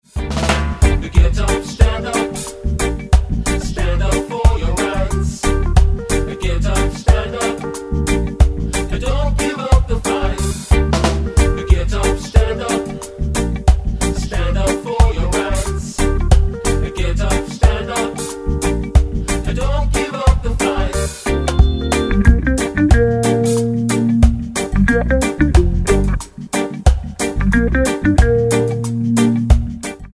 Tonart:Gm mit Chor